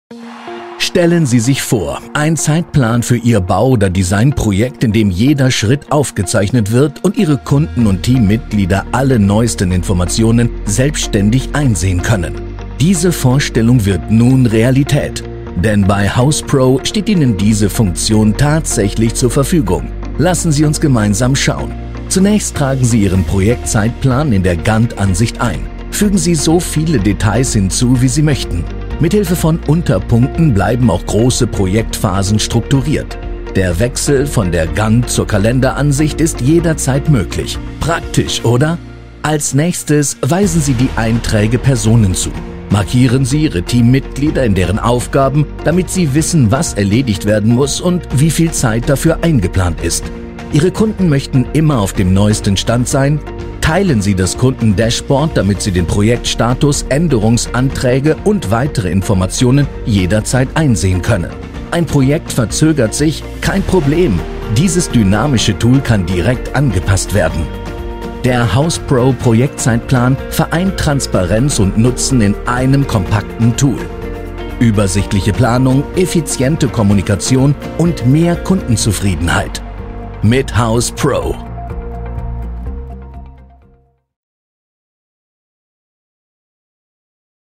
Männlich
Unternehmensvideos
Im mittleren Alter
EmotionalMächtigLeidenschaftlich